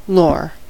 lore: Wikimedia Commons US English Pronunciations
En-us-lore.WAV